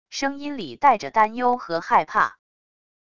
声音里带着担忧和害怕wav音频